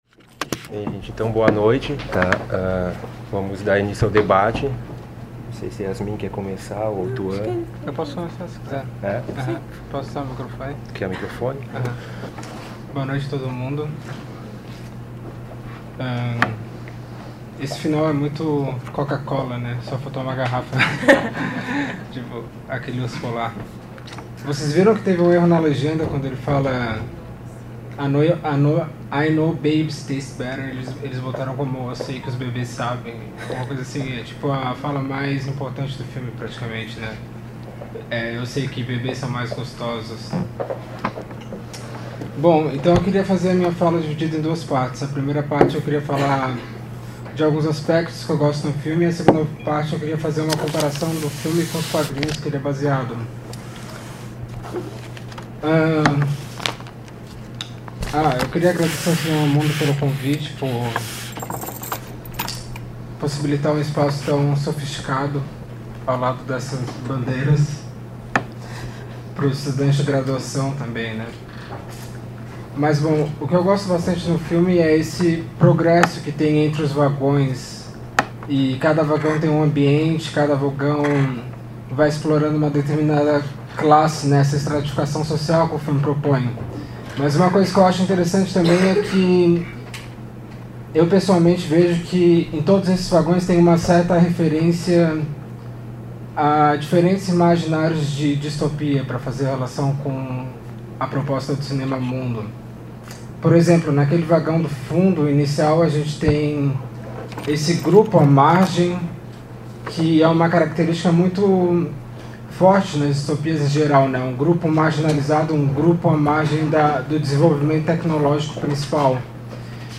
Open Debate A3